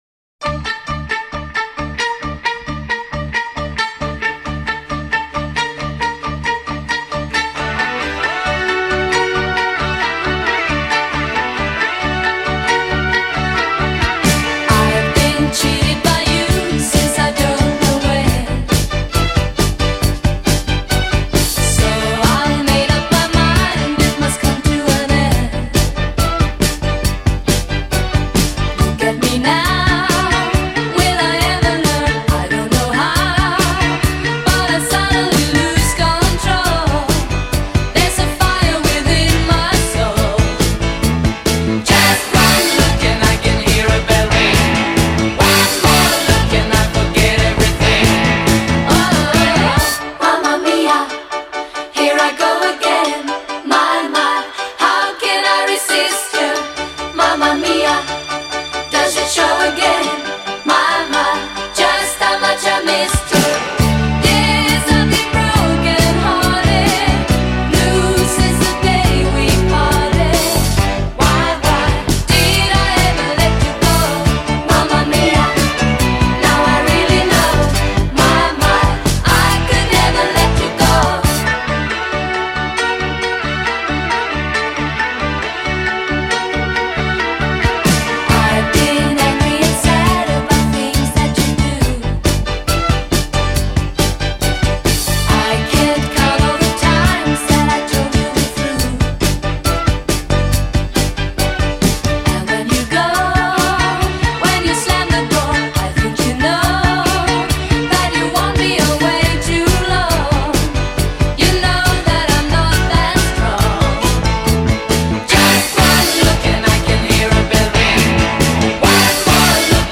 Pop, Classic Pop, Disco